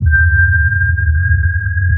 sonarPingSuitVeryClose1.ogg